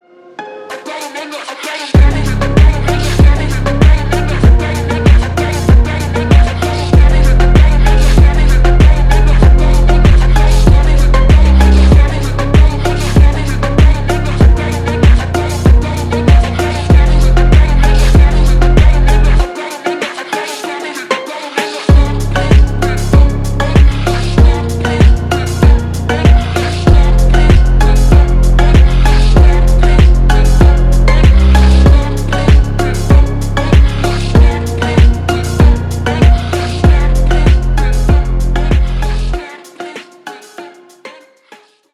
• Качество: 320, Stereo
мощные
качающие
phonk
Популярный кавер на популярный фонк из Тик тока